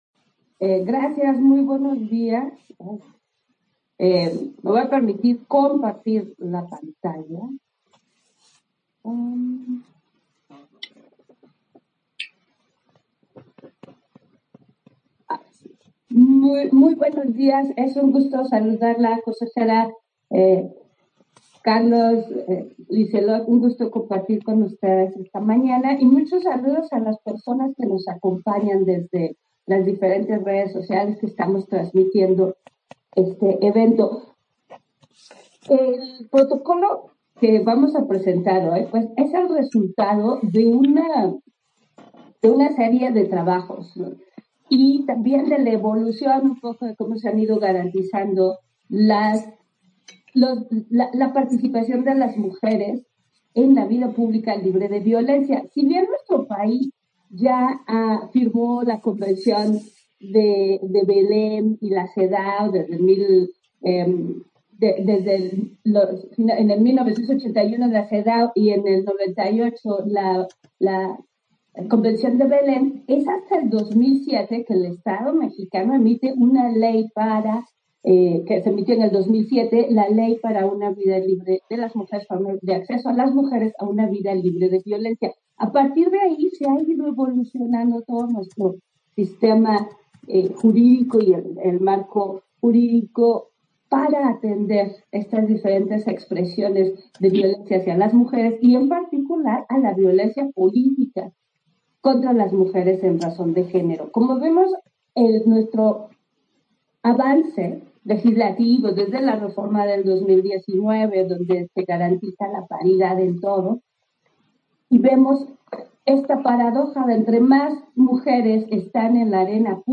Intervención de Norma de la Cruz, en la presentación del protocolo para la atención de víctimas y para la elaboración del análisis de riesgo en los casos de violencia política contra las mujeres, en la 1era. Feria del libro, Igualdad de género y democracia